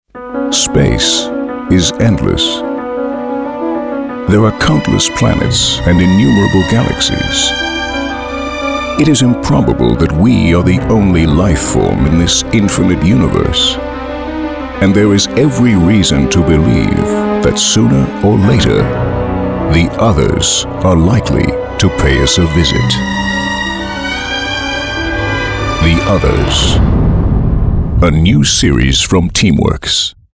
amerikanischer Profi-Sprecher.
Sprechprobe: Industrie (Muttersprache):
english (us) voice over talent.